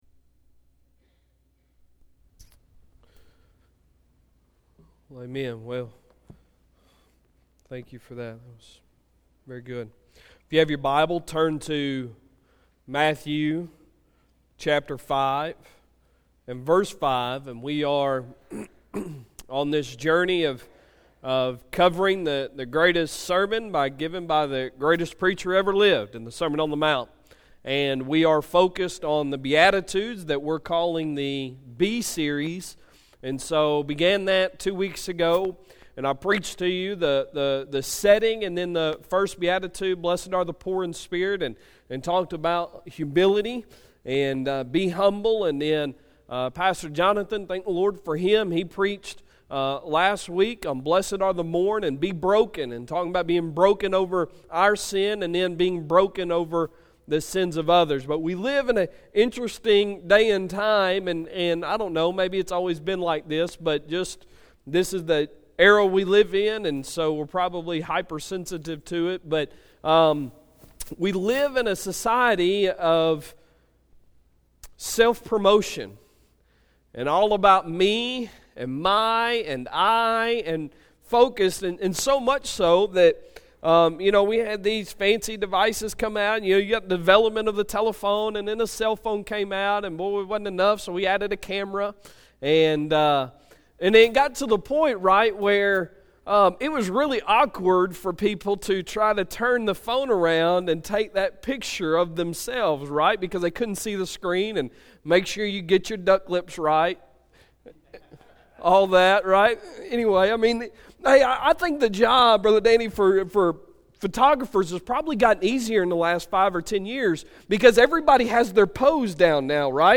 Sunday Sermon September 30, 2018